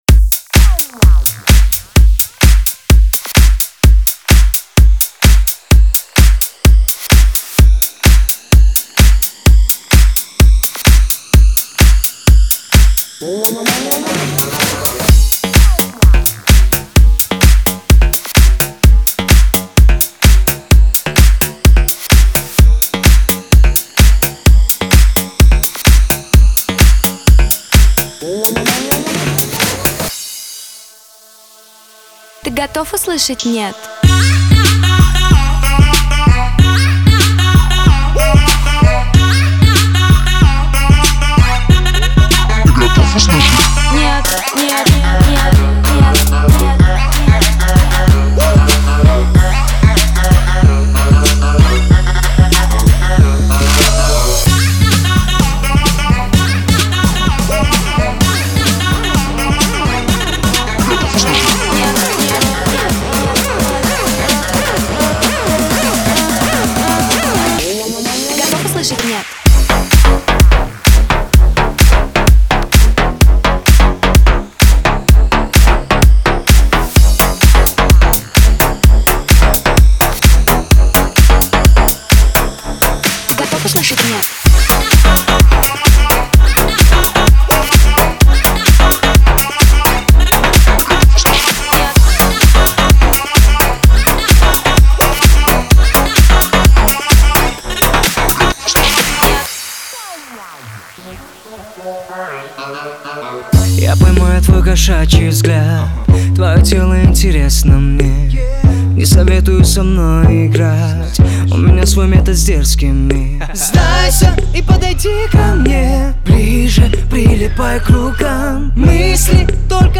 Плейлисти: Клубна музика